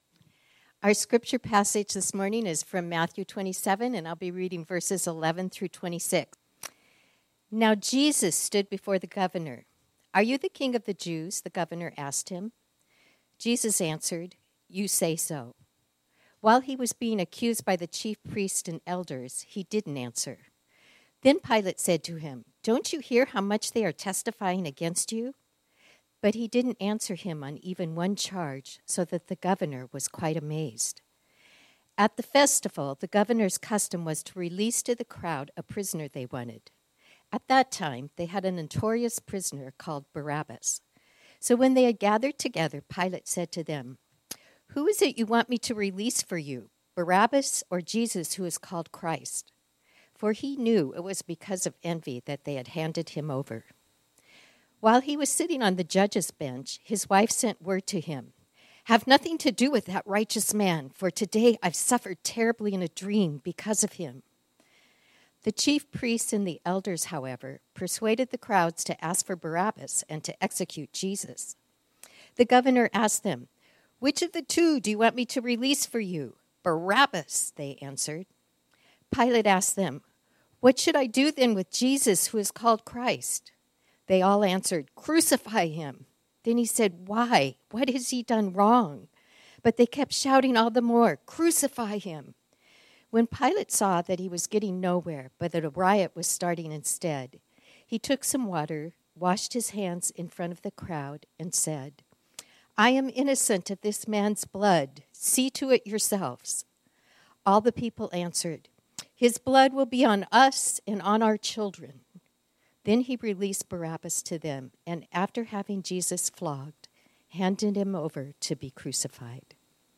This sermon was originally preached on Sunday, November 17, 2024.